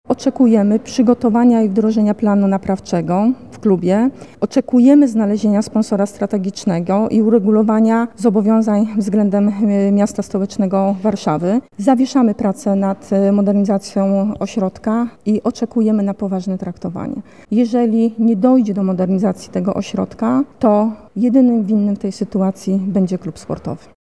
Władze stolicy wystąpiły do Polonii o wyjaśnienia dotyczące sponsora oraz planu naprawczego – mówi wiceprezydent Warszawy Renata Kaznowska.